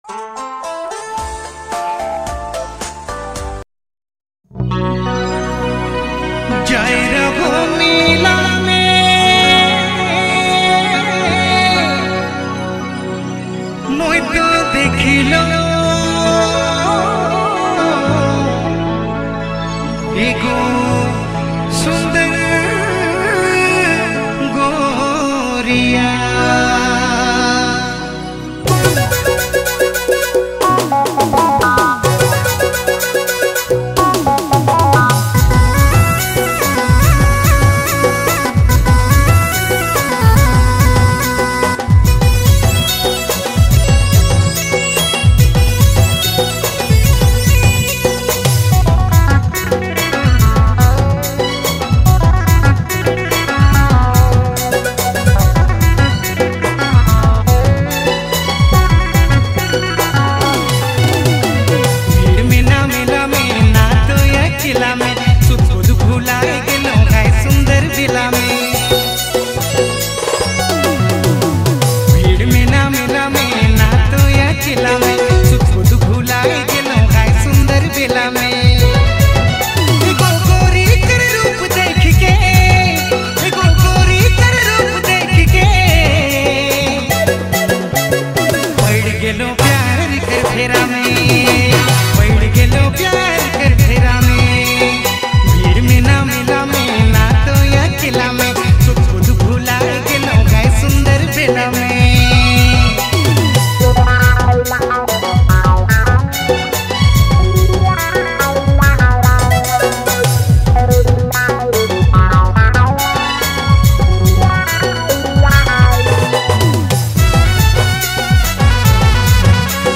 Dj Remixer
New Latest Nagpuri Song